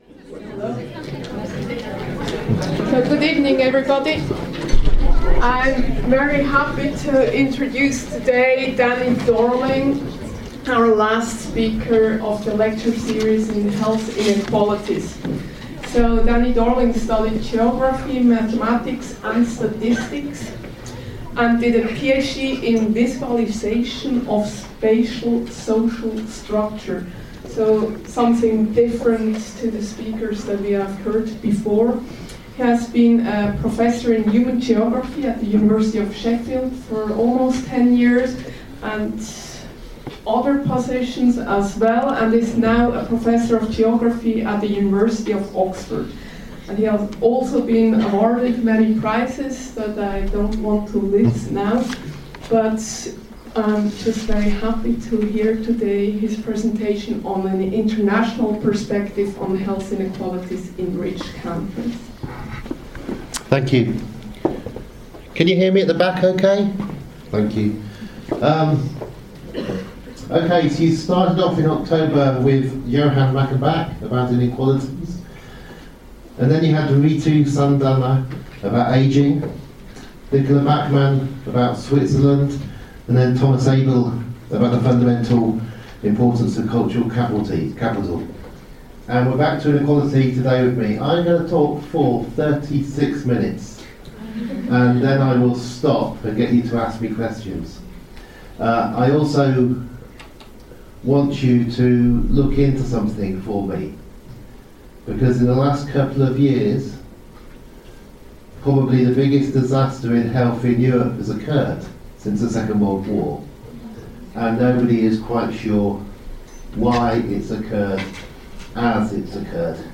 University of Lucerne public lecture series on health inequalities, Luzern, Switzerland, December 13th 2017.